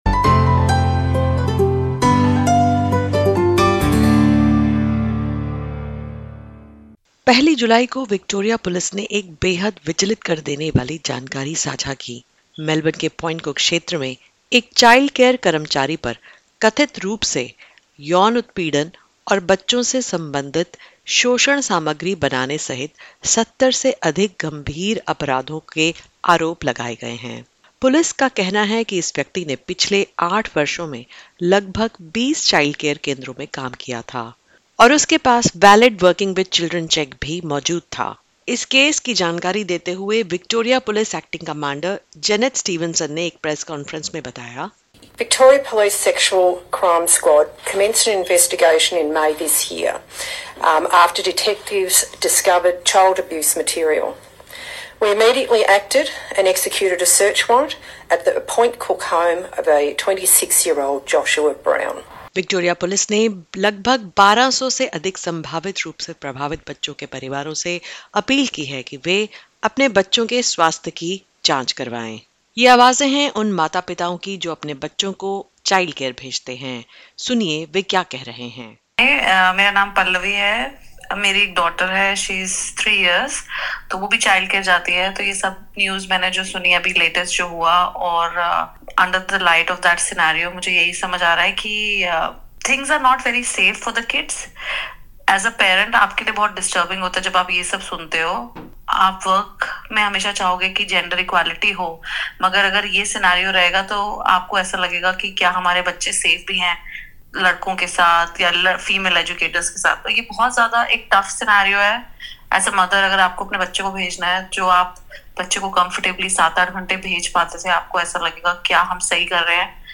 The Victorian government is taking action after a childcare worker was charged with multiple alleged child sexual abuse offences, potentially affecting several families. In this podcast, we hear from concerned mothers calling for stronger safety and security measures at childcare centres.